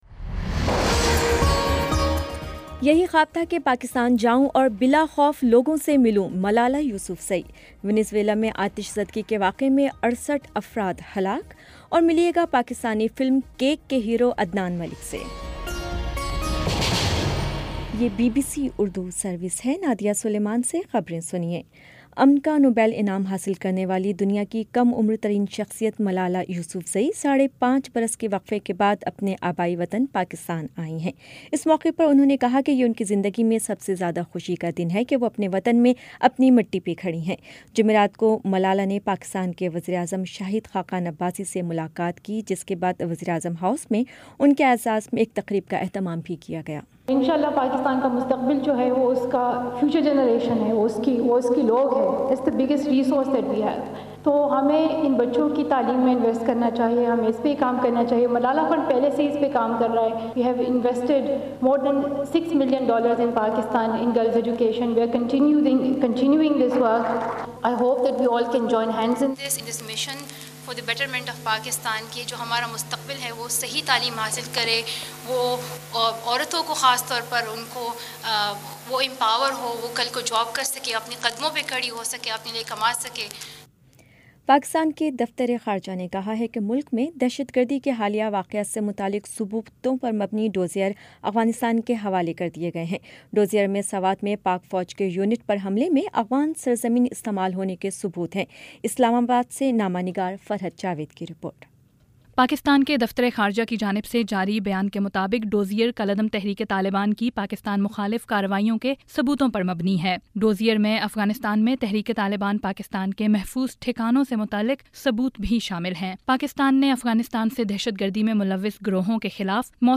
مارچ 29 : شام پانچ بجے کا نیوز بُلیٹن